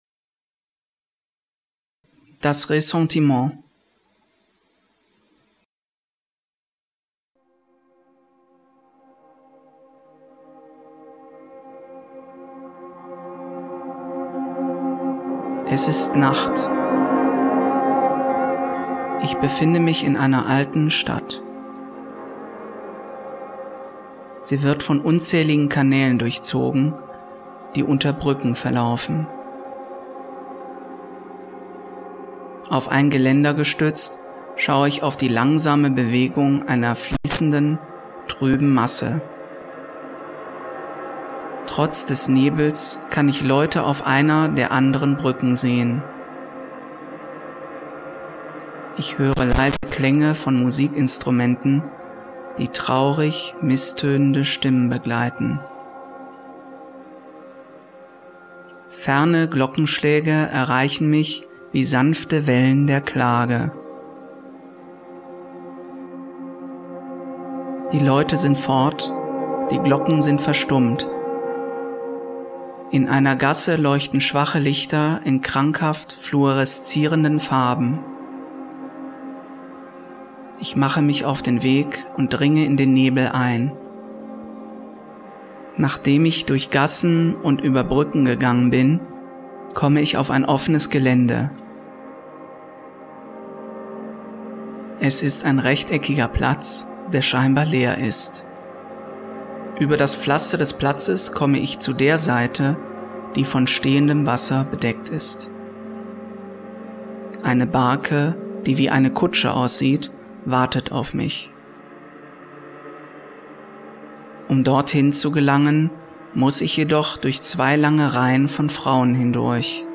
Geleitete Erfahrung Es ist Nacht.